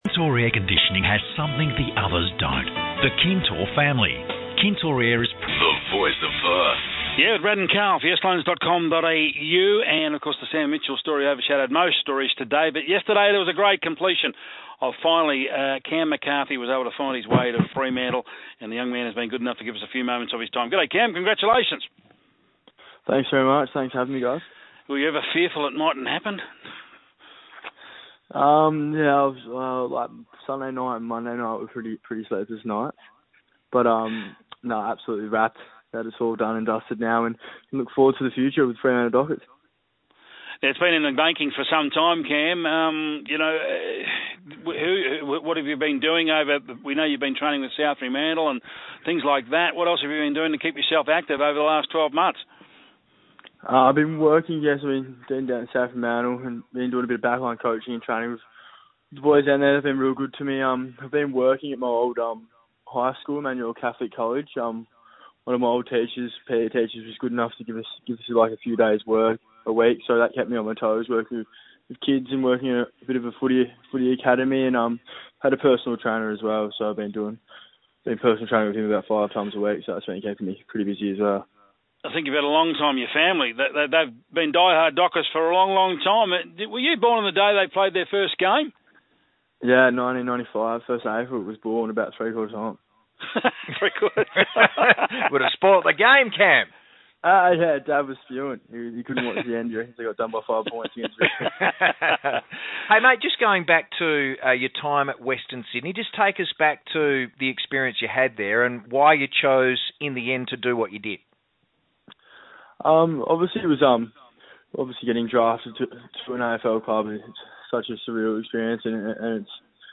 Freo's new recruit Cam McCarthy speaks to Brad Hardie and Karl Langdon after being traded to the Freo Dockers.